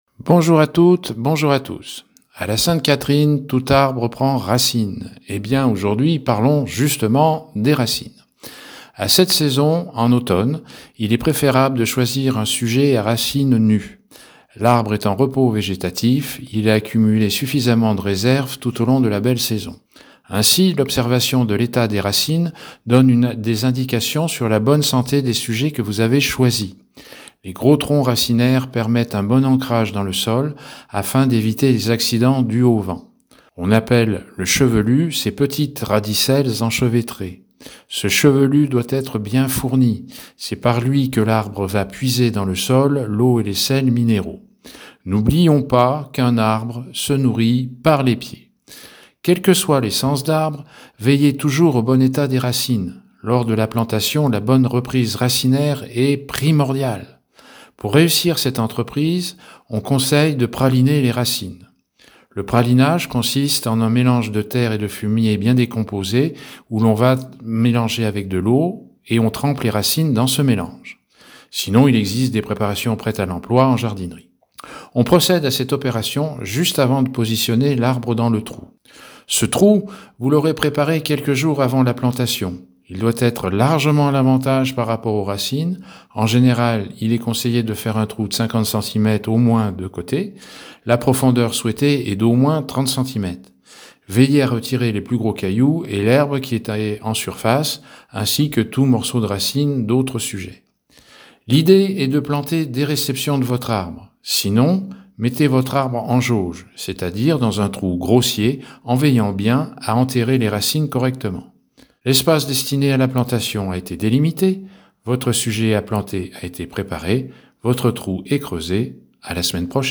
Dans la chronique RMB de ce mardi 26 novembre sur les plantes, on poursuit notre tour des conseils et informations concernant la plantation d'un arbre, avec aujourd'hui un point particulier sur les racines...